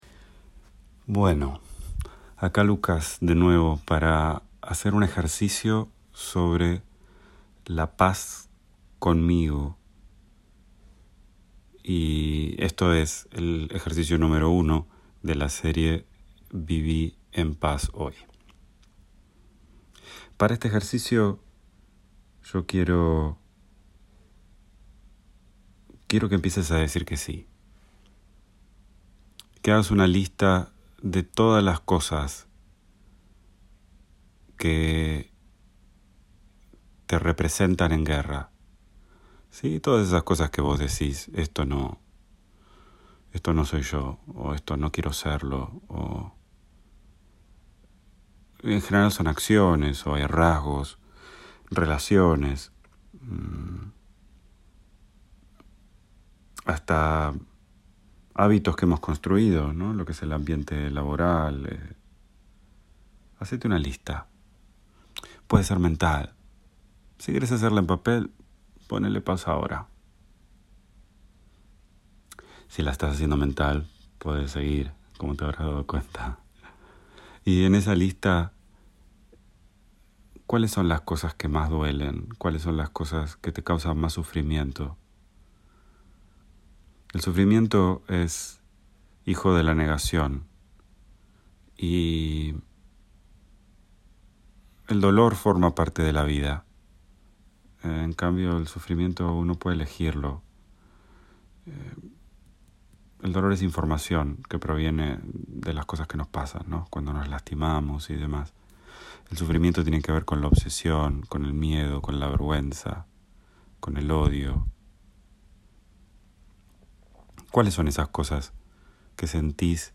Música